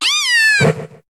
Cri de Flamiaou dans Pokémon HOME.